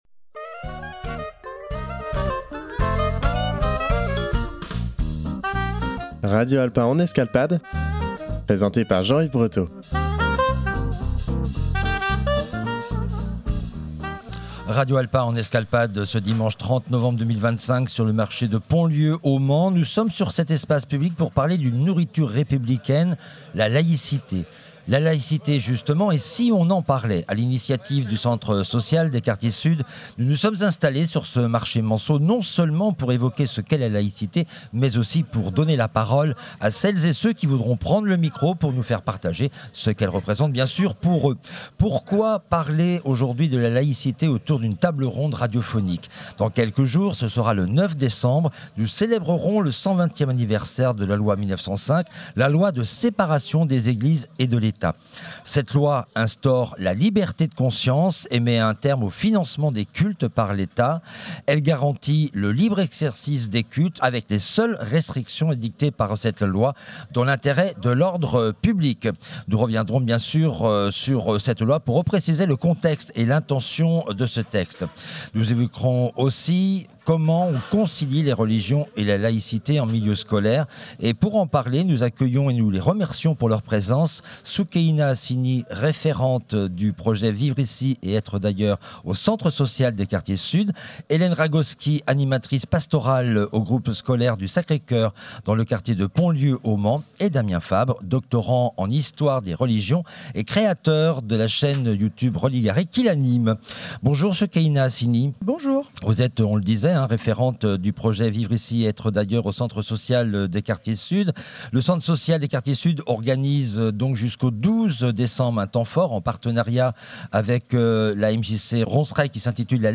Radio Alpa s’est installée sur cet espace public pour parler d’une nourriture républicaine : la laïcité.
Pourquoi parler de la laïcité autour d’une table ronde radiophonique ?
Dans cette émission, vous entendrez des témoignages des habitantes et des habitants des quartiers Sud du Mans (Quartier Prioritaire Politique de la Ville).